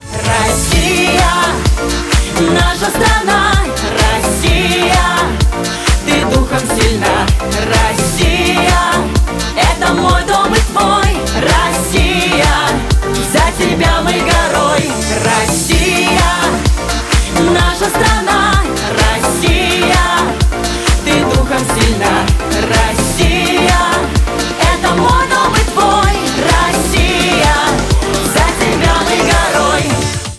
мотивирующие
патриотические
русская попса
поп-музыка